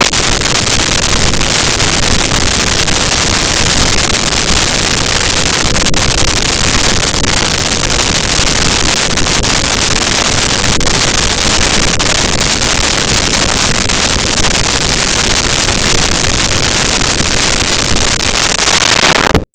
Underwater 02
Underwater 02.wav